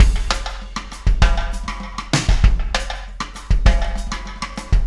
Pulsar Beat 27.wav